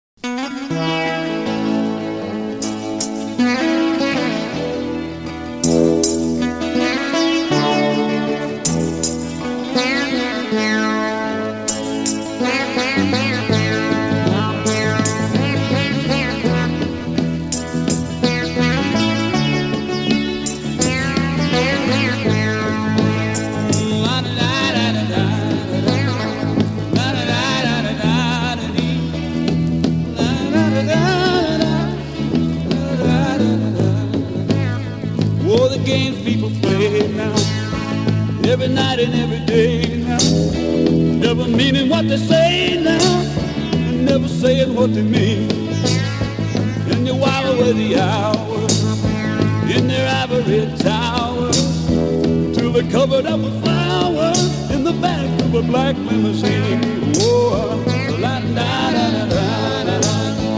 60'S MALE